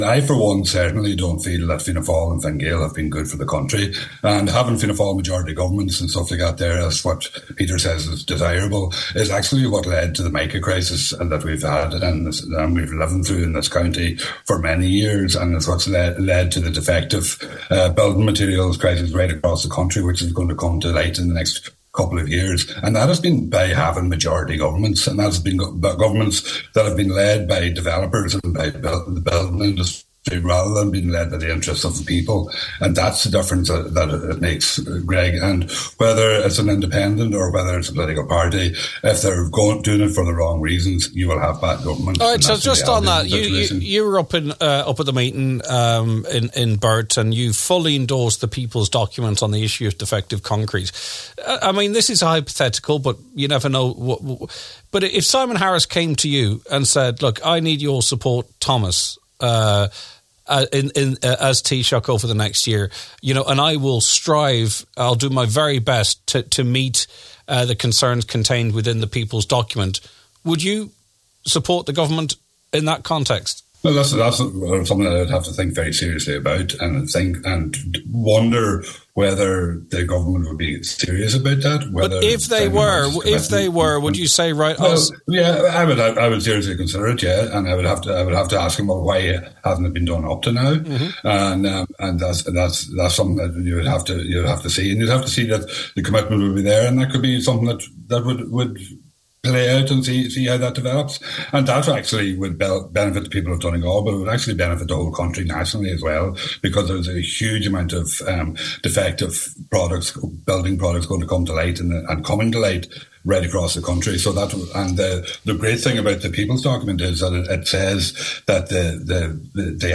However, Deputy Pringle told today’s Nine til Noon Show he would have a number of serious questions, not least why the government hadn’t done so already.